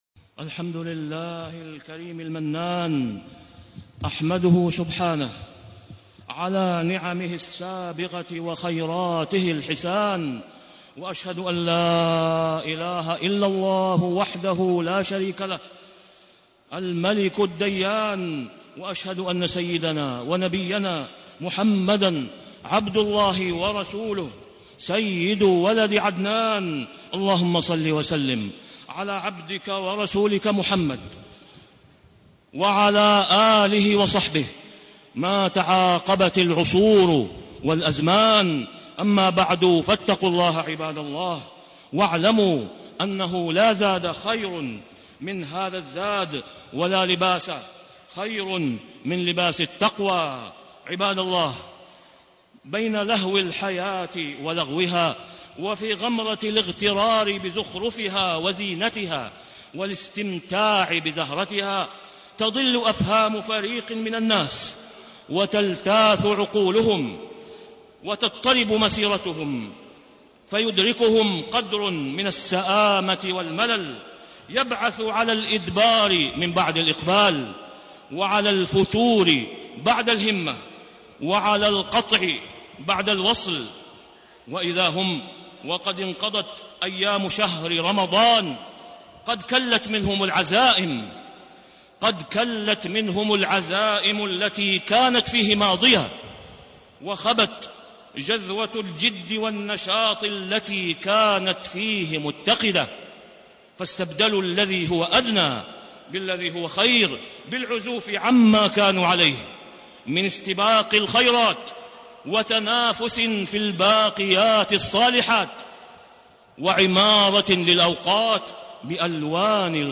تاريخ النشر ٥ شوال ١٤٣٥ هـ المكان: المسجد الحرام الشيخ: فضيلة الشيخ د. أسامة بن عبدالله خياط فضيلة الشيخ د. أسامة بن عبدالله خياط المداومة على الطاعات بعد رمضان